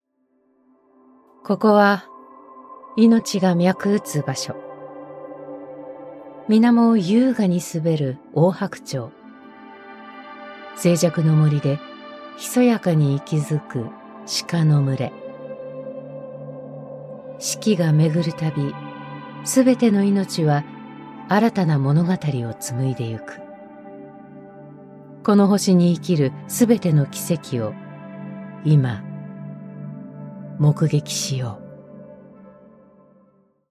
Erzählung
Ihre strahlende, tiefe Stimme hat eine ausgeprägte Überzeugungskraft und einen vertrauenerweckenden Klang.